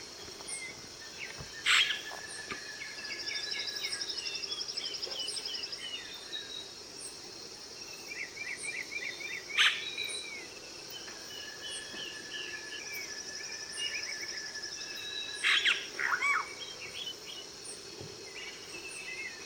Yellow-rumped Cacique (Cacicus cela)
Location or protected area: Parque Nacional Yasuní
Condition: Wild
Certainty: Recorded vocal
cacicus-cela.mp3